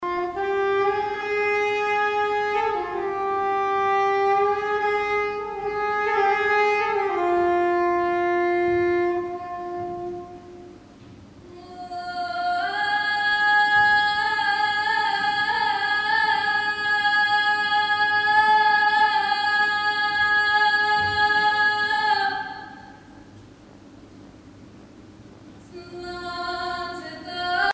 Chanson bulgare des montagnes
Concert a Brugges Festival, Belgique
Pièce musicale inédite